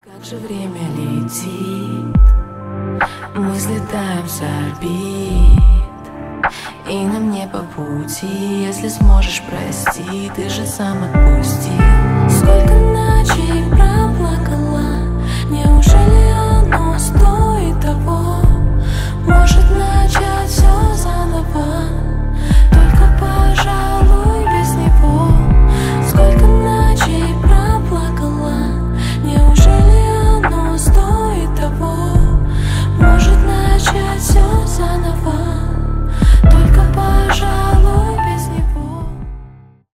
• Качество: 320 kbps, Stereo
Поп Музыка
спокойные
грустные